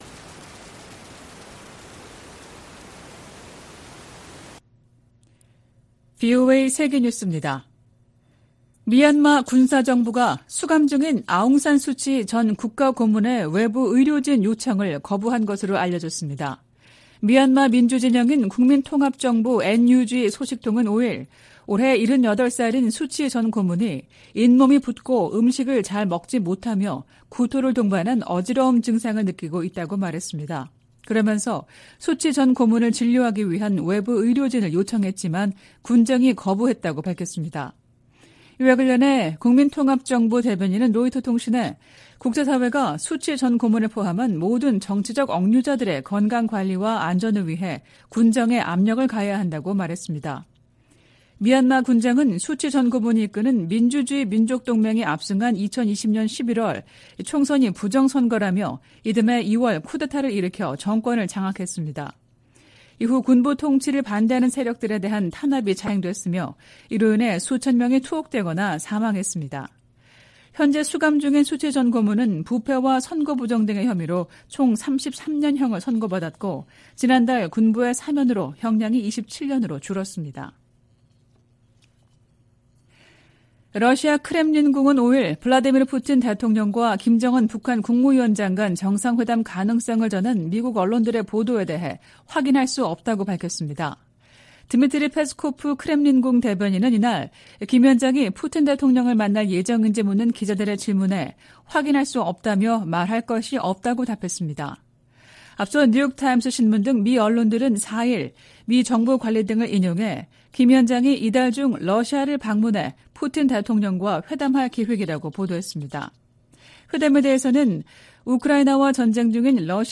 VOA 한국어 '출발 뉴스 쇼', 2023년 9월 6일 방송입니다. 백악관은 북한 김정은 위원장의 러시아 방문에 관한 정보를 입수했다고 밝혔습니다. 미 국무부는 북한과 러시아의 연합 군사훈련 논의 가능성을 비판했습니다.